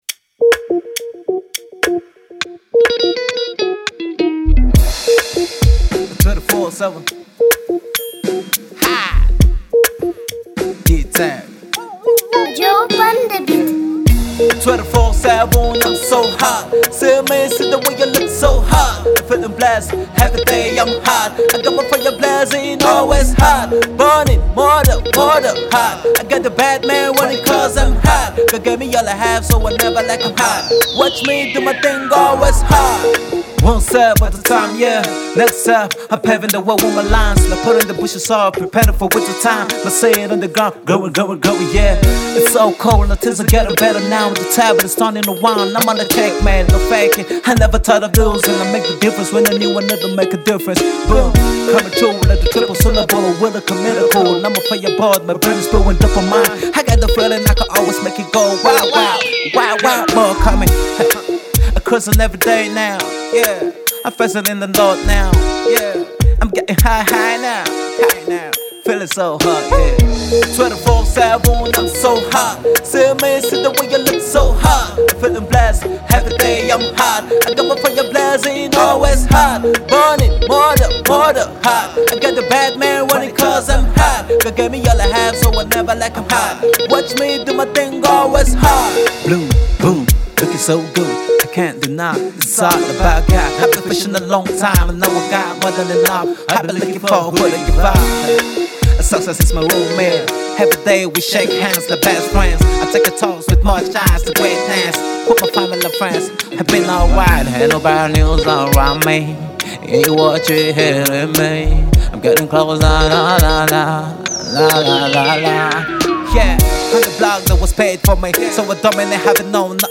Emerging northern rapper
real rap and hip hop